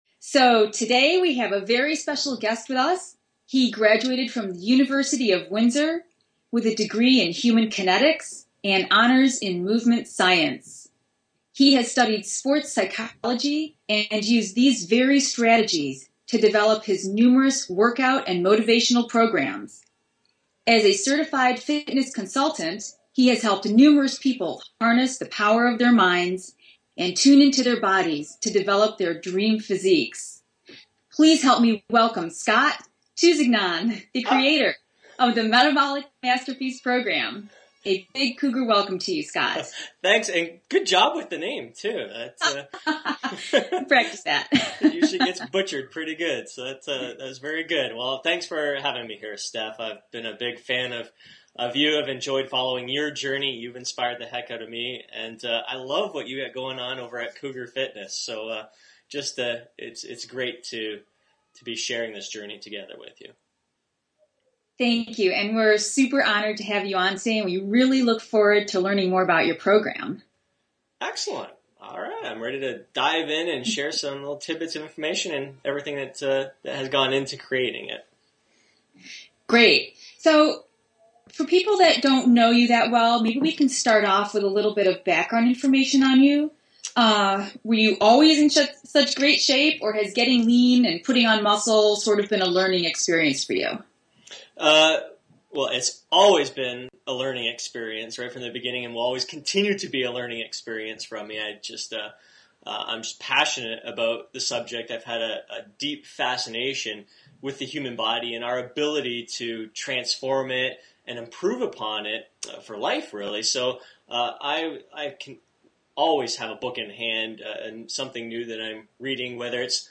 MetabolicInterview.mp3